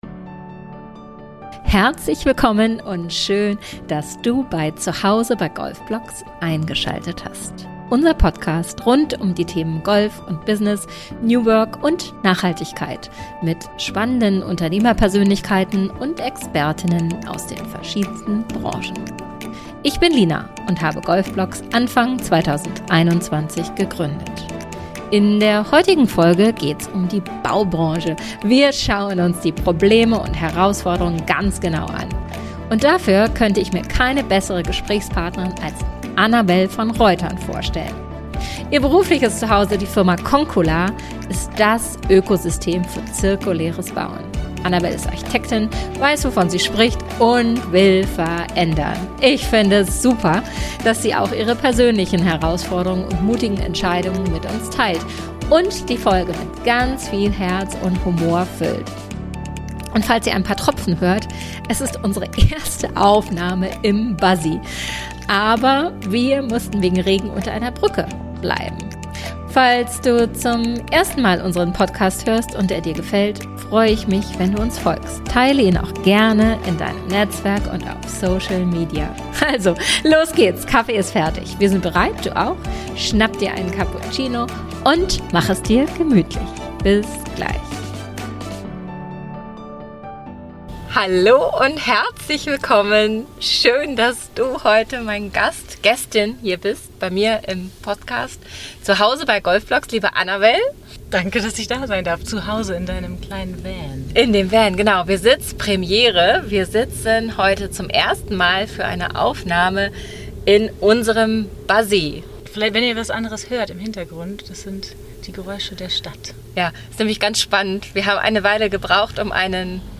Stichwort: VERBAND FÜR BAUEN IM BESTAND e. V. Und solltet ihr ab und an ein paar Regentropfen hören, dann kann das gut sein. Denn es war die erste Podcast-Aufnahme im Buzzy und es schüttete aus Eimern, zum Glück fanden wir Unterschlupf unter einer Brücke.